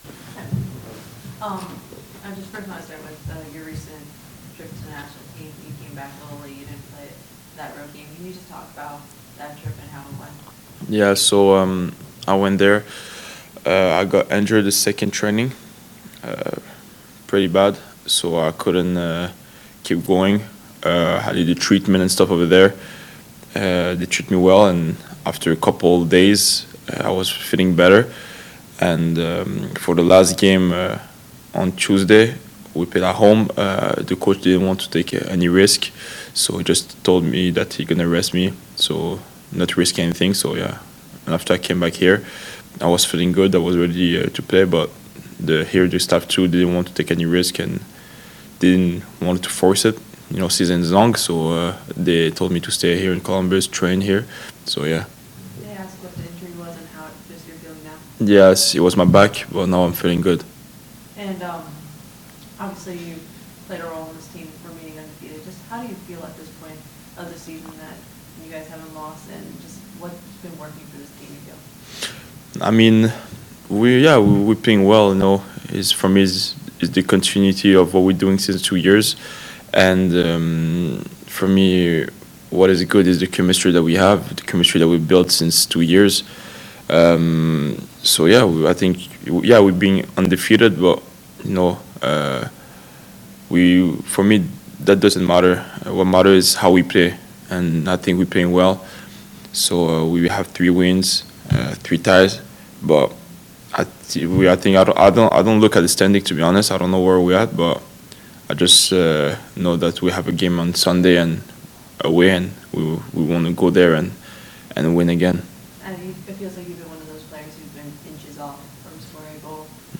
talking to media about match at St. Louis City on April 12, 2025